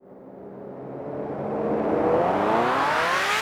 MB Trans FX (14).wav